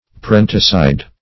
Meaning of parenticide. parenticide synonyms, pronunciation, spelling and more from Free Dictionary.
Search Result for " parenticide" : The Collaborative International Dictionary of English v.0.48: Parenticide \Pa*ren"ti*cide\, n. [L. parenticida a parricide; parens parent + caedere to kill.]